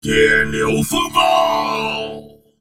文件 文件历史 文件用途 全域文件用途 Enjo_skill_06_2.ogg （Ogg Vorbis声音文件，长度2.6秒，106 kbps，文件大小：34 KB） 源地址:地下城与勇士游戏语音 文件历史 点击某个日期/时间查看对应时刻的文件。